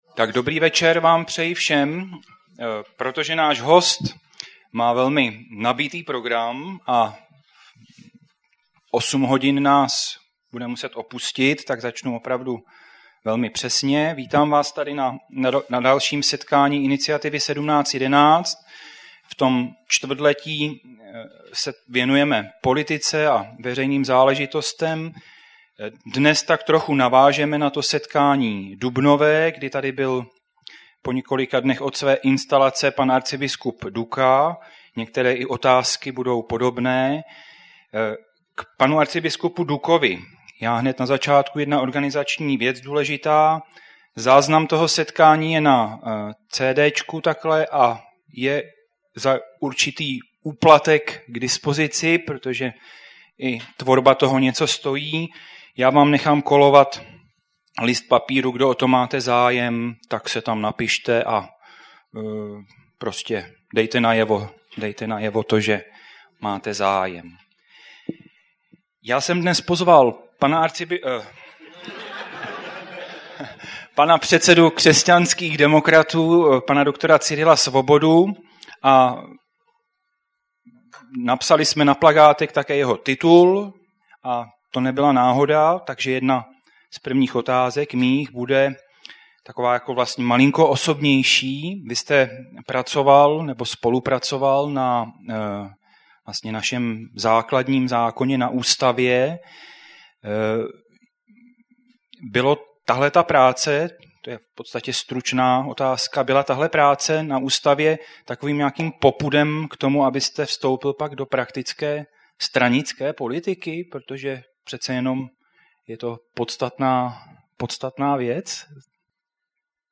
Zvukový záznam přednášky
Hostem setkání byl předseda KDU-ČSL JUDr. Cyril Svoboda.